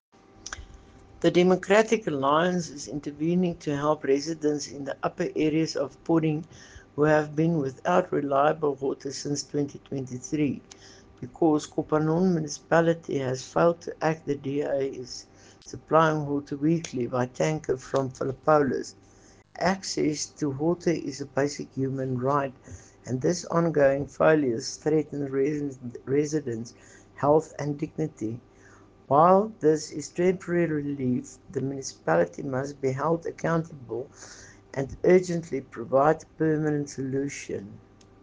Afrikaans soundbites by Cllr Estelle Noordman and